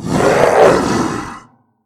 hell_dog3.ogg